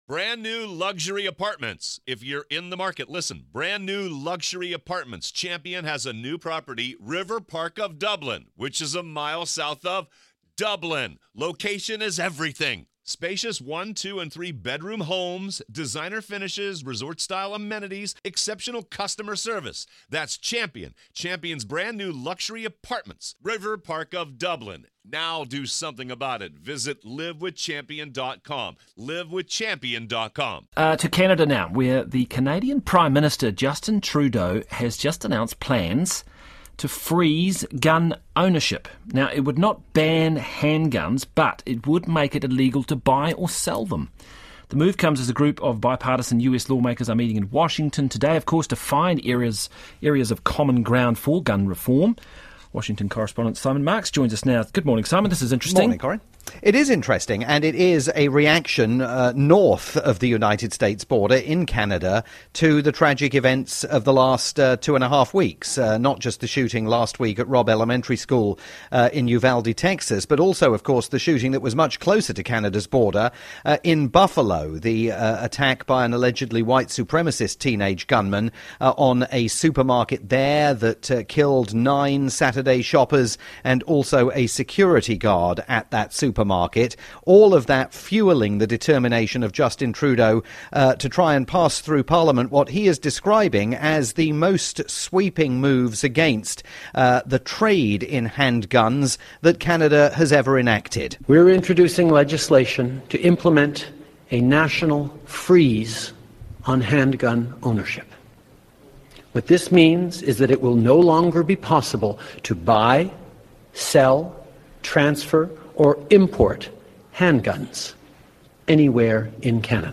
live report for Radio New Zealand's "Morning Report"